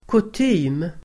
Ladda ner uttalet
Uttal: [kut'y:m]
kutym.mp3